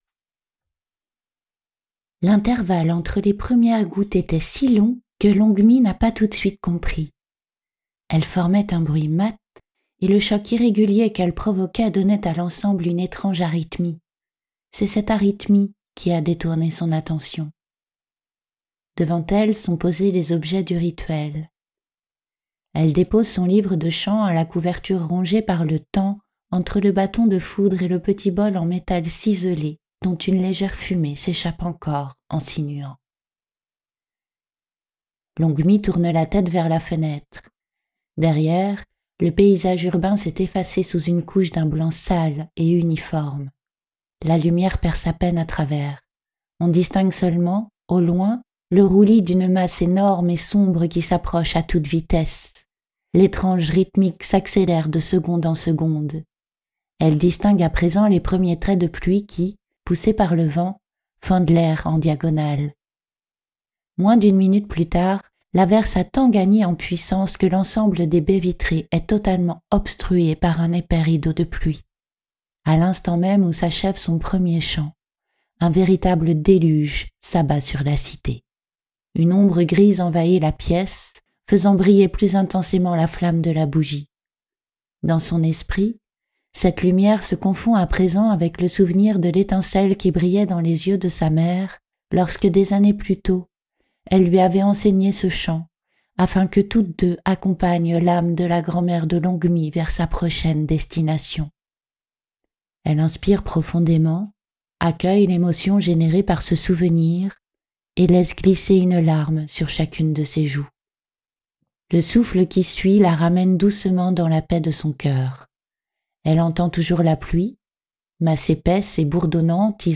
Les extraits ci-dessous sont issus de mon propre texte, Les yeux dans Ajna, et explorent d’autres registres de narration, entre conte philosophique et atmosphère symbolique.
Passage narratif
Atmosphère symbolique, rituel d' accompagnement au “passage”